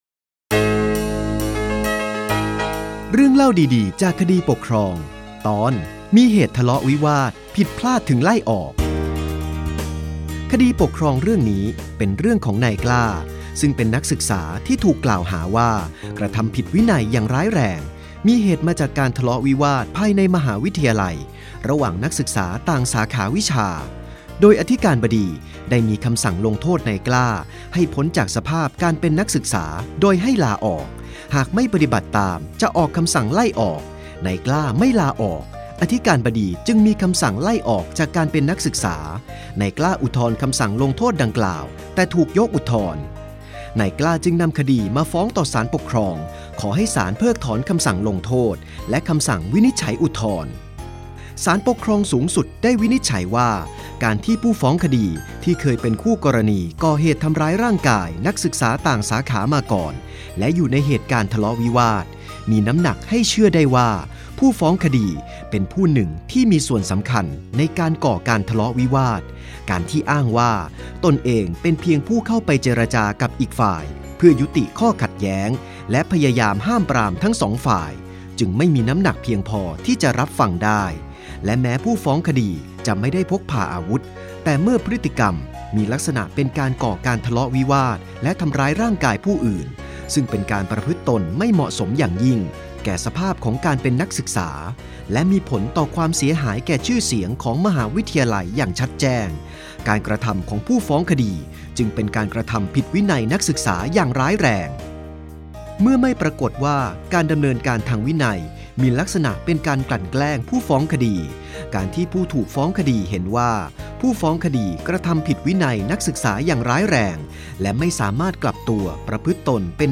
สารคดีเสียง เรื่องเล่าดีดีจากคดีปกครอง 4 ตอนที่ 1 - 5